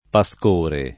vai all'elenco alfabetico delle voci ingrandisci il carattere 100% rimpicciolisci il carattere stampa invia tramite posta elettronica codividi su Facebook pascore [ pa S k 1 re ] s. m. — ant. gallicismo poet. per «primavera»